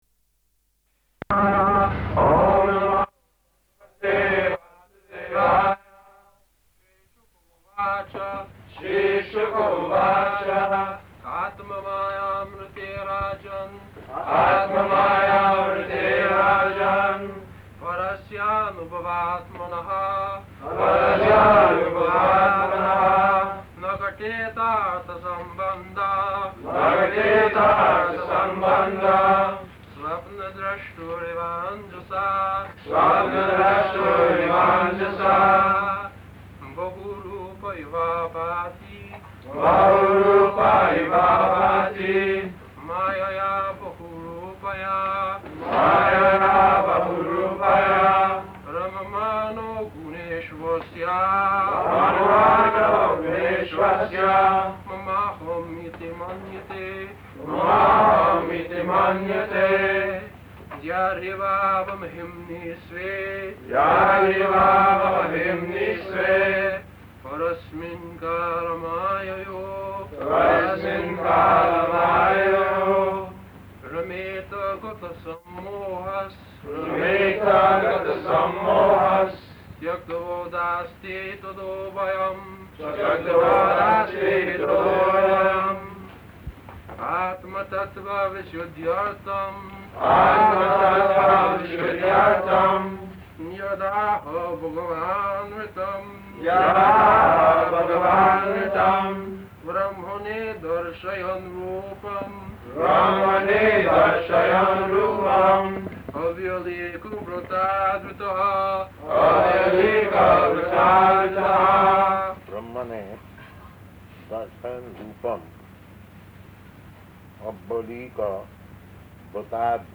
April 25th 1972 Location: Tokyo Audio file
[leads chanting of verses]